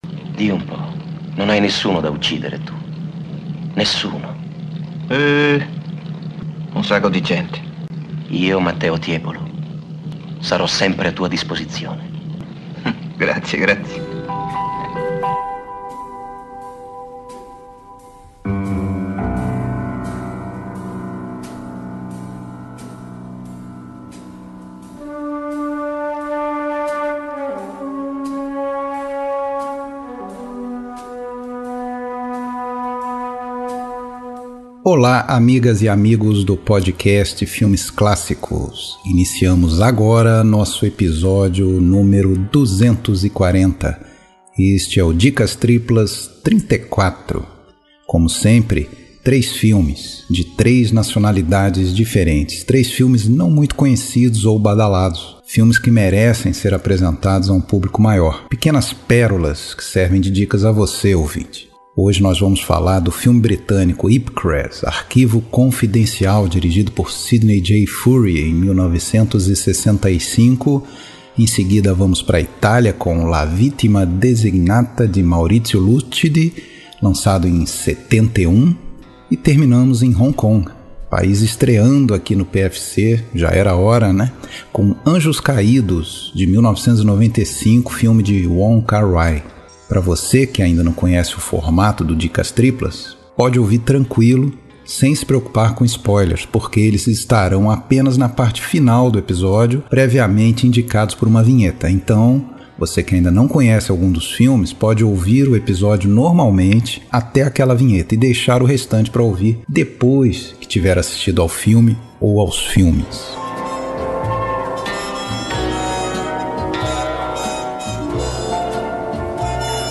Trilha Sonora: Trilhas sonoras dos filmes comentados neste episódio e outras composições.